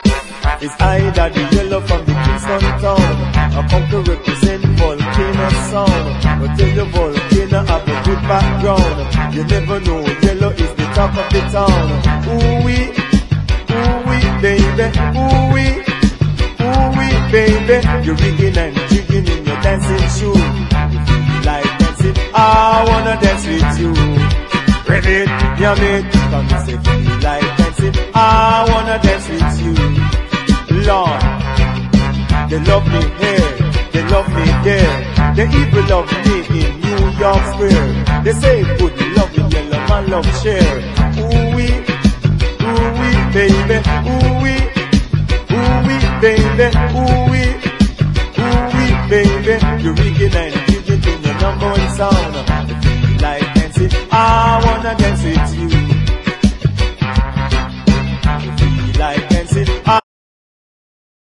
WORLD / REGGAE / DANCEHALL